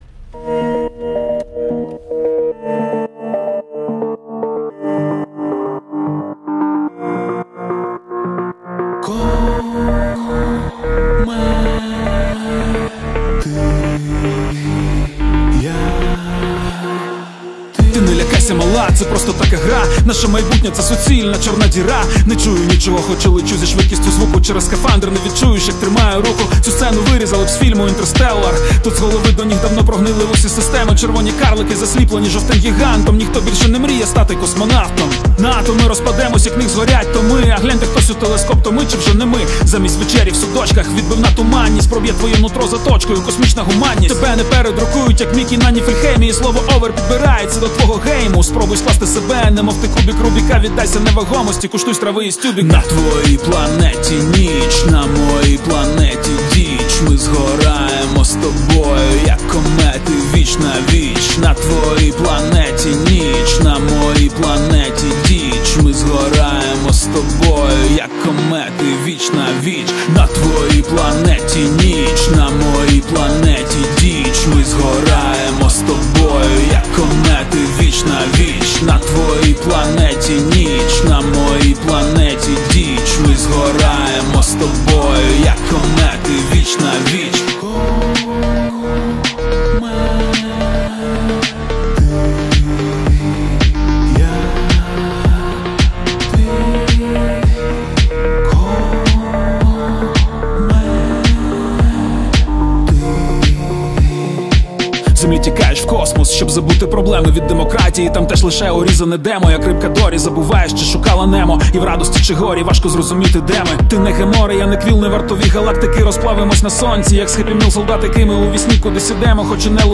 • Жанр:Реп